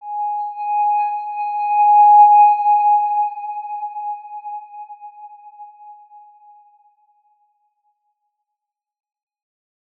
X_Windwistle-G#4-pp.wav